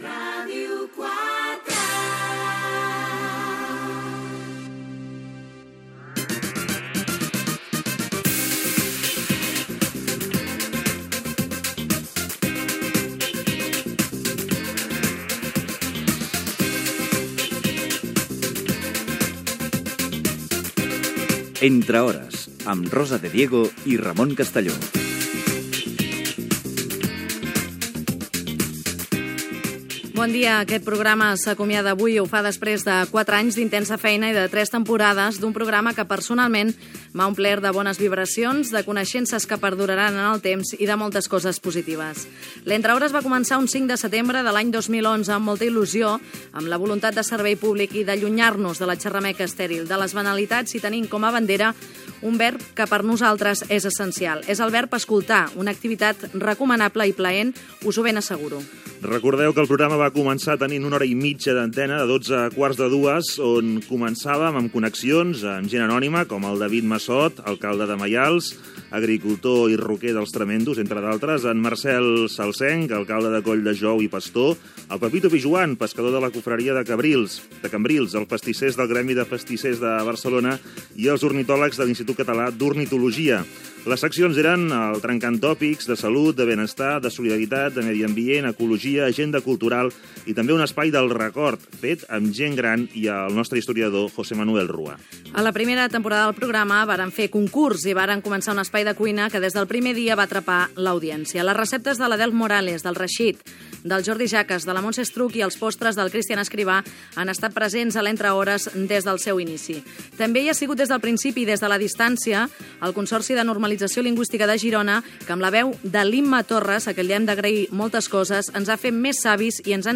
Indicatius de la ràdio i del programa, presentació de l'última edició del programa repassant la feina feta en tres temporades
Info-entreteniment